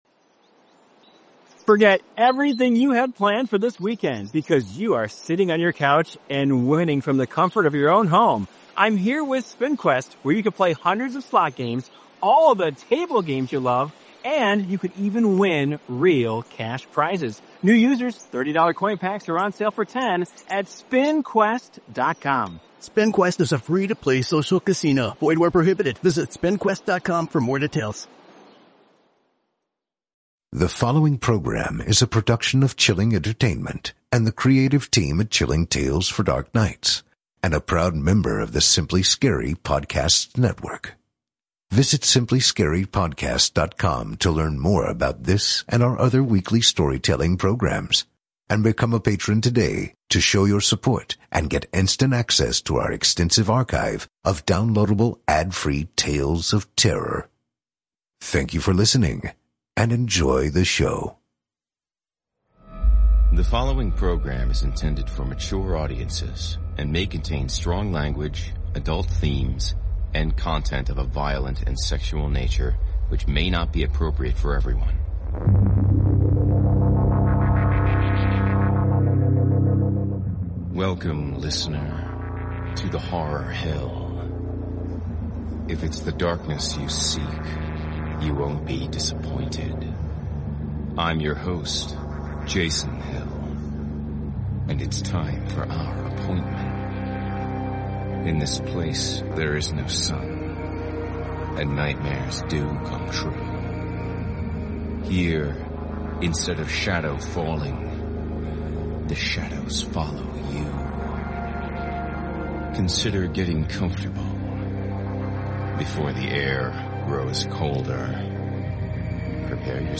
A Horror Anthology and Scary Stories Series Podcast